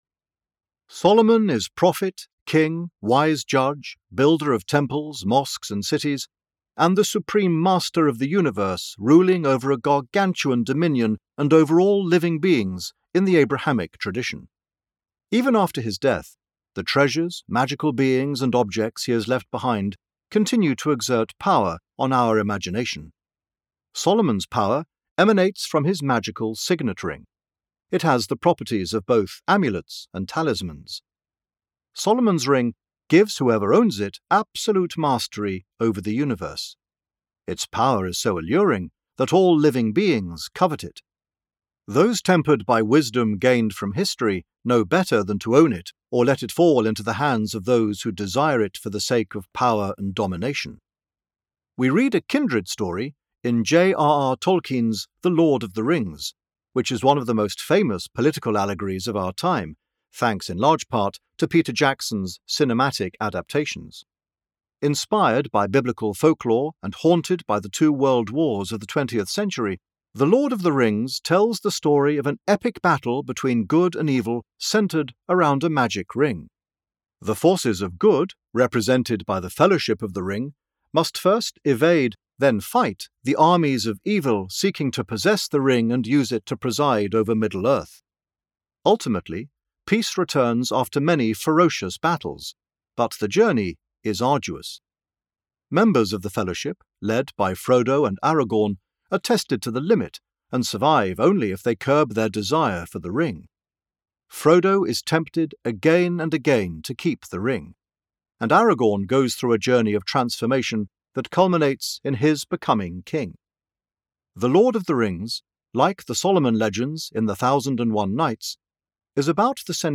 Ethical living through stories book cover for audiobook